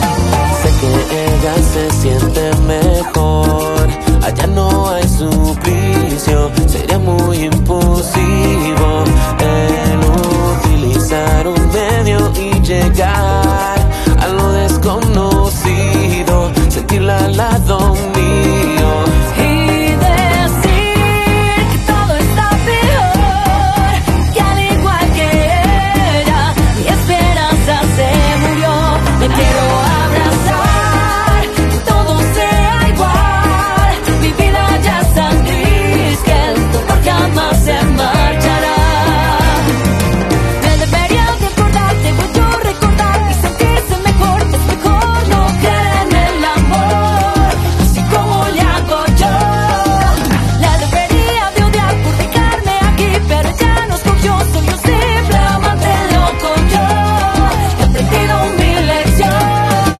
en Metro Copilco con invitados de lujo